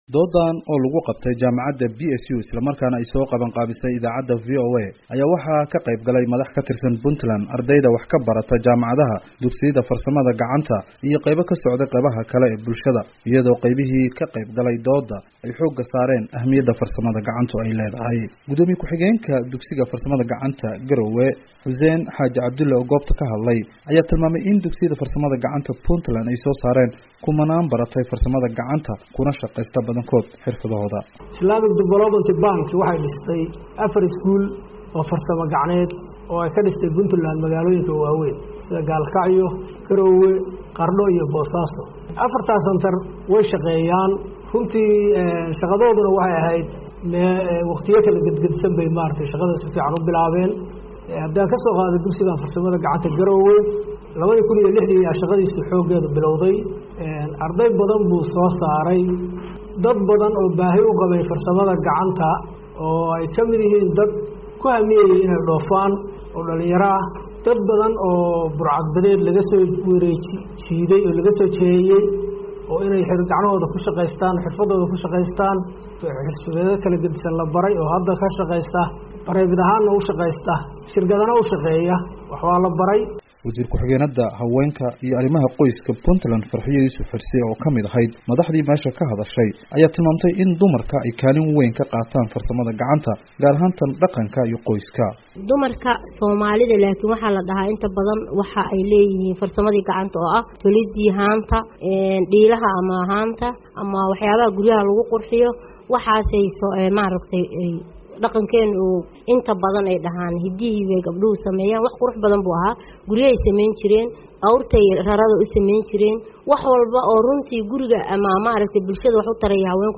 Garowe: VOA Oo Kulan Dadweyne Qabatay
Kulankan oo ay ka qeyb galeen dadweyne badan, ayaa waxaa looga hadlayey ahmiyadda farsamada gacanta.